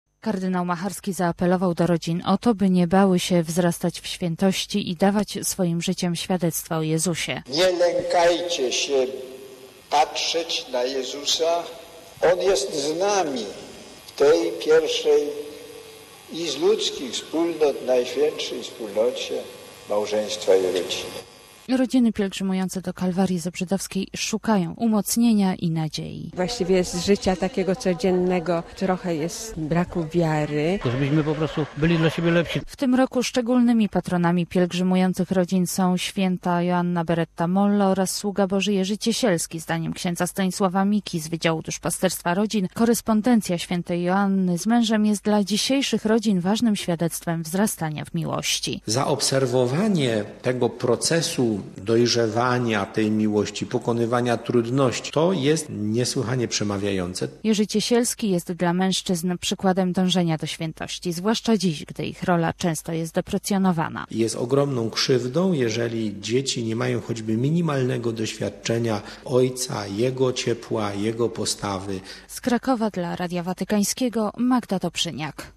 Pod hasłem “Naśladować Chrystusa w rodzinie” rodziny archidiecezji krakowskiej pielgrzymowały do Kalwarii Zebrzydowskiej. - To jest miejsce i chwila, w której opowiadamy się po waszej stronie, rodziny! - mówił kardynał Franciszek Macharski do kilkudziesięciu tysięcy pielgrzymów zgromadzonych w kalwaryjskim sanktuarium. Relacja